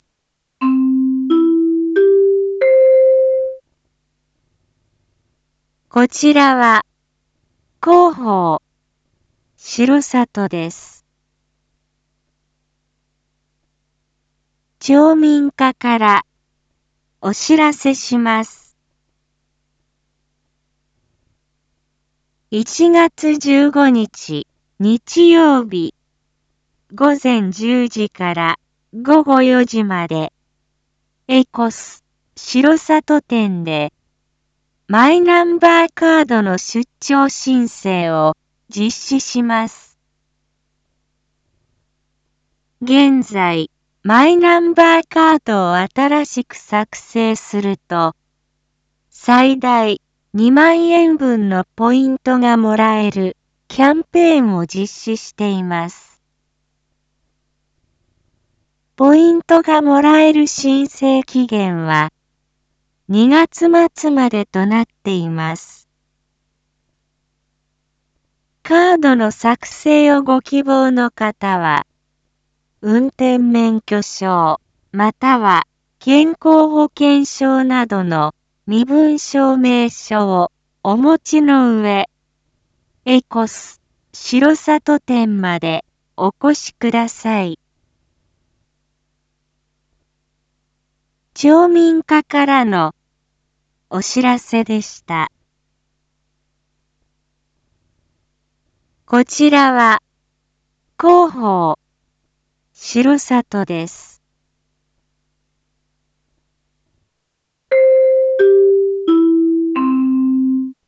一般放送情報
Back Home 一般放送情報 音声放送 再生 一般放送情報 登録日時：2023-01-13 19:01:52 タイトル：R5.1.13 19時放送分 インフォメーション：こちらは、広報しろさとです。